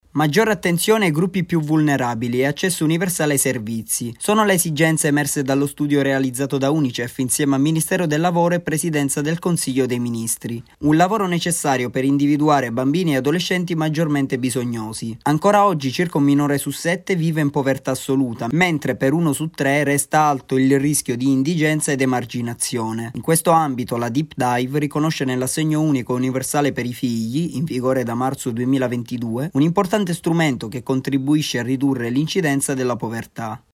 Ieri presentata la Deep Dive, un’analisi sulle misure di prevenzione e contrasto della povertà minorile in Italia. Il servizio